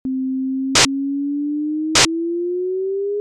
Поэтому в следующем эксперименте использовалась та же синусоида, на этот раз повышающаяся по высоте вдоль хроматической шкалы.
Я все еще могу различить, что в синусоидальной волне есть прерывание, но оно не кажется таким очевидным, как в предыдущем эксперименте.